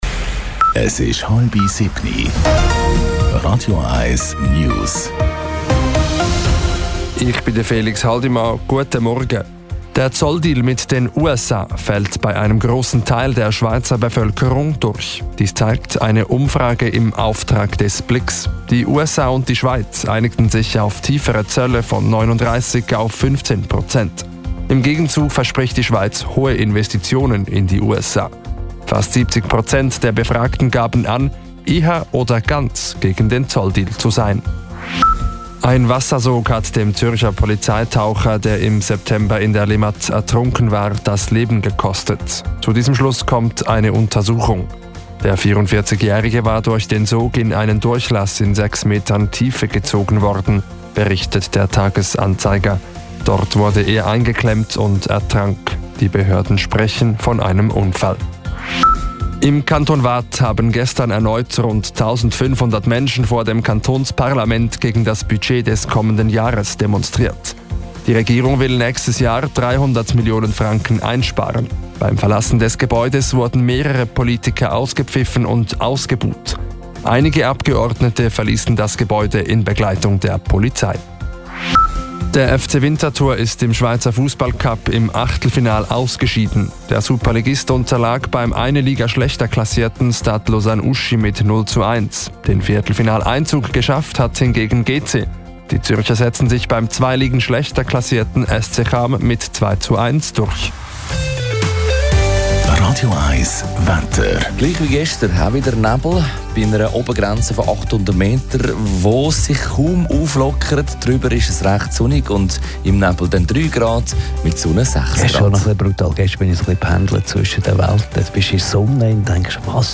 Die letzten News von Radio 1